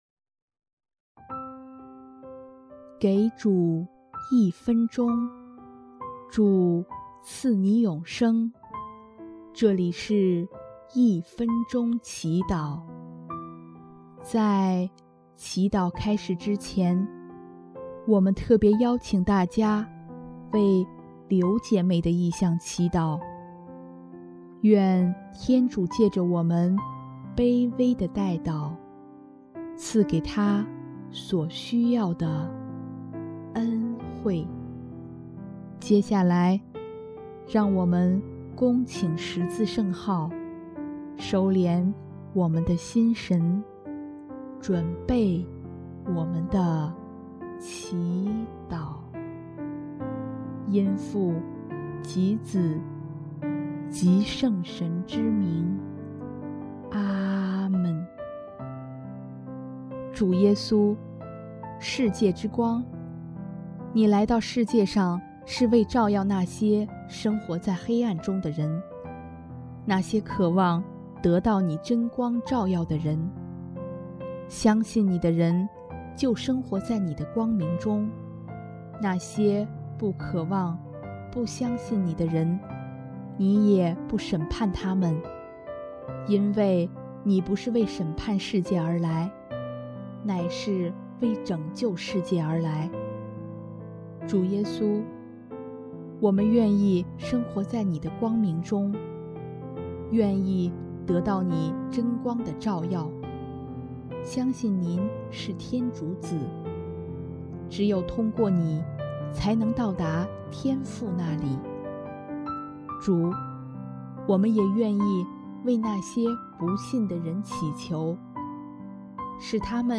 【一分钟祈祷】|4月24日 世界之光，我们信赖你
音乐：第一届华语圣歌大赛参赛歌曲《你永遠的陪伴》